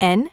OCEFIAudio_en_LetterN.wav